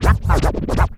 scratch03.wav